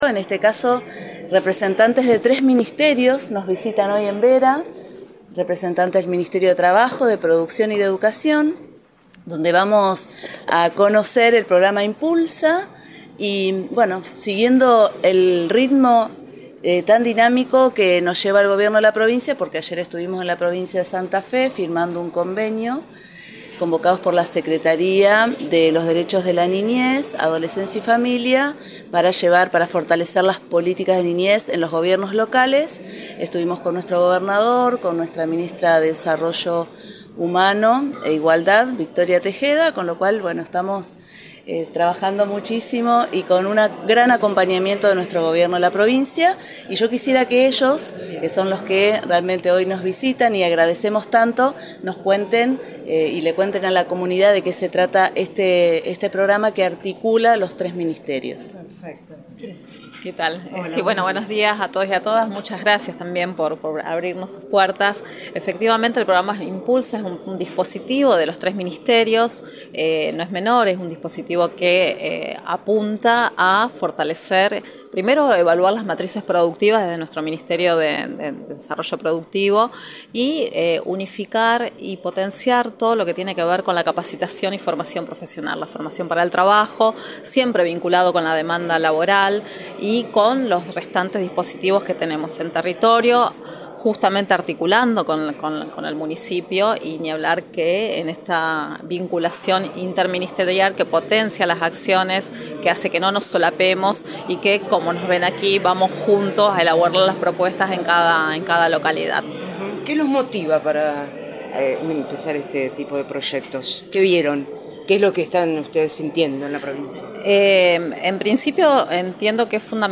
Convocados por la Municipalidad de Vera, estuvieron dando una charla informativa Gonzalo Cristini, director provincial de Nuevas Economías del Ministerio de la Producción; Florentina González, directora de Vinculación Educativa; Valentina Peresín, subsecretaria de Aprendizaje Continuo del Ministerio de Educación y Gabriela Estrella, Subsecretaria de Capacitación y Trabajo Decente del Ministerio de Trabajo.